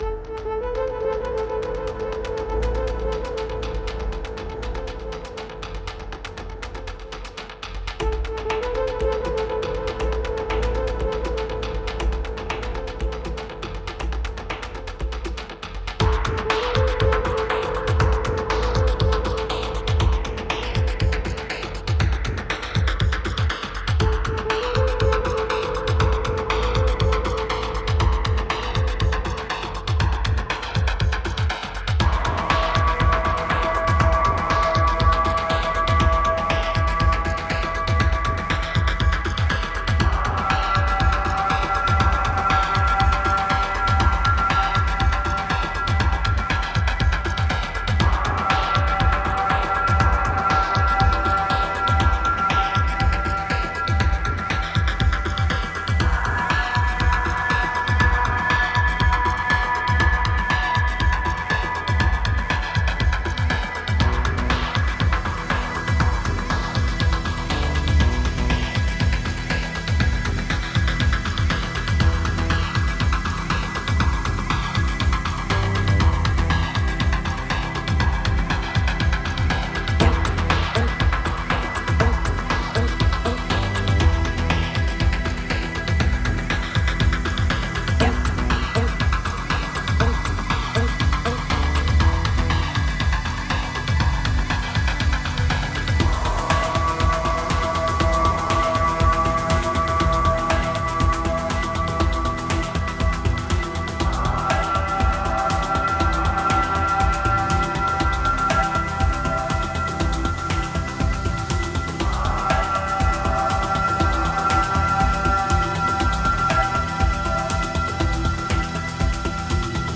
Genre: Electronic, IDM.